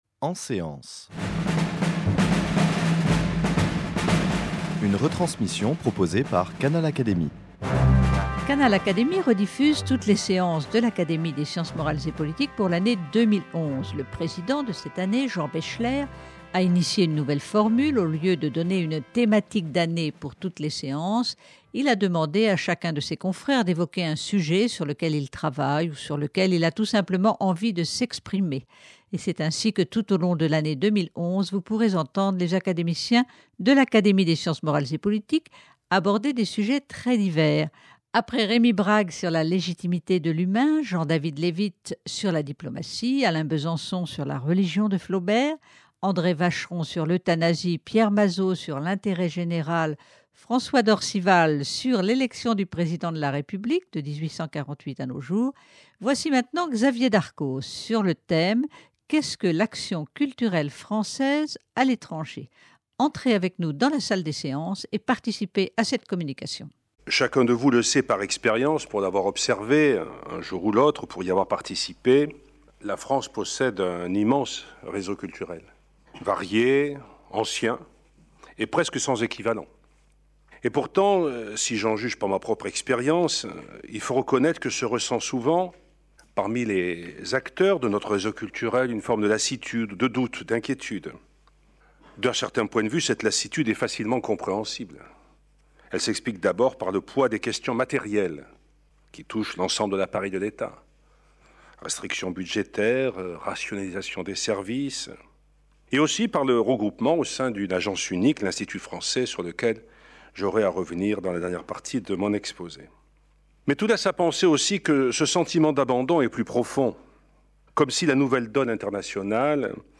Xavier Darcos préside le nouvel Institut français qui regroupe désormais l’ensemble des services qui oeuvraient pour la politique culturelle de la France à l’étranger. Il convient d’abord de clarifier le sens de cette action et de lui fixer un cap raisonnable, avec des missions et des projets réalistes compte tenu du contexte international. Voici la retransmission intégrale de sa communication devant l’Académie des sciences morales et politiques du lundi 7 mars 2011.